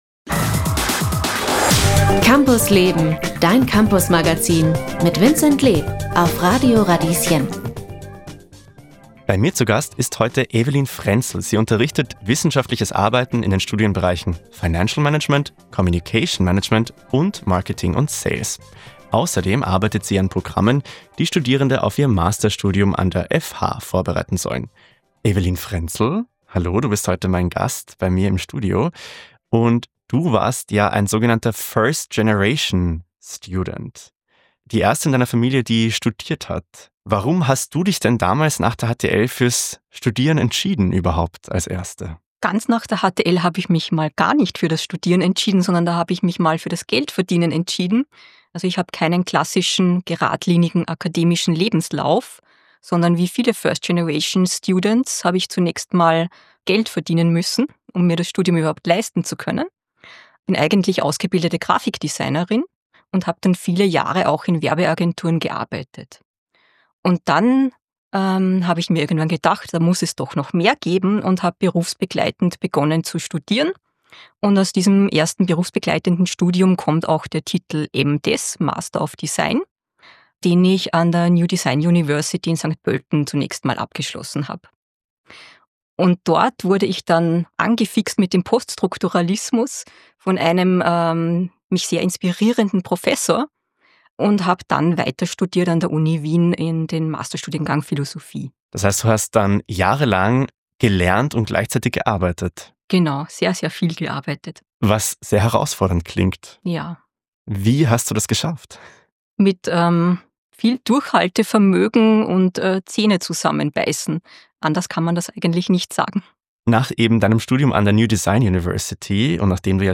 Dieser Podcast ist ein Ausschnitt aus der Campus Leben-Radiosendung vom 9. April 2025.